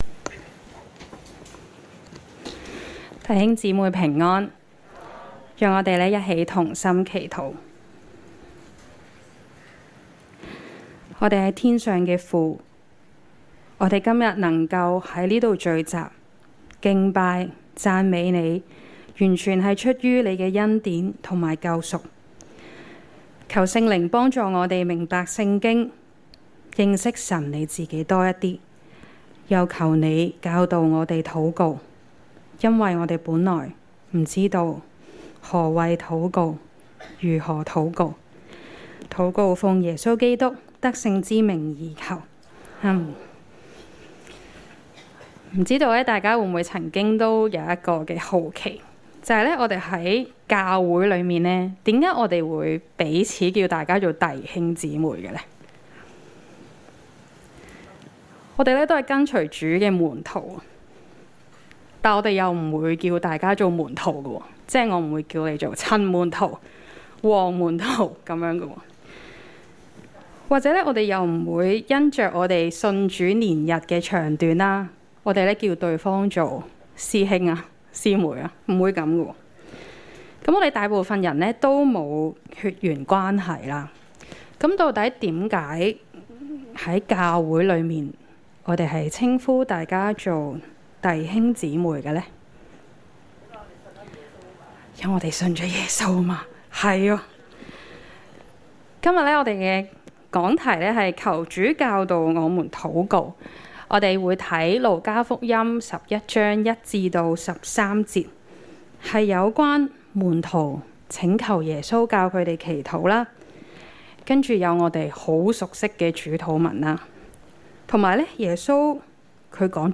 證道重溫
恩福东九堂崇拜-早、午堂